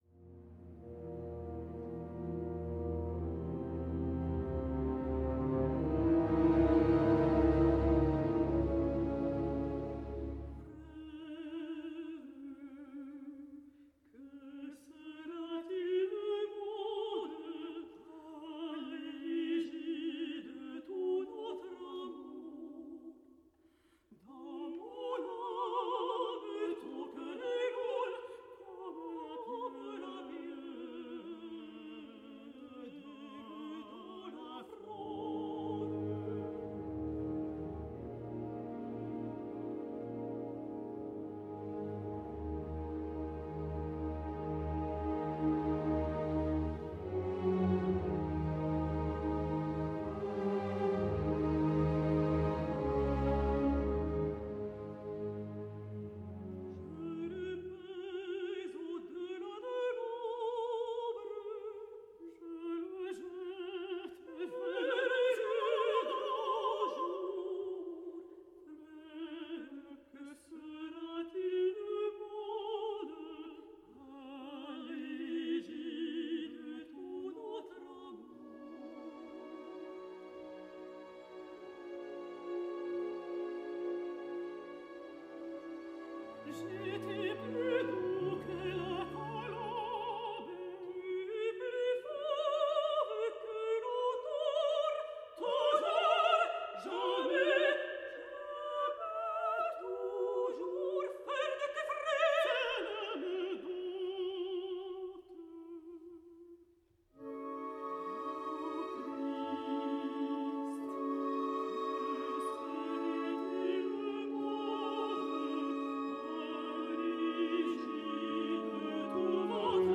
Ma carrière de chanteuse lyrique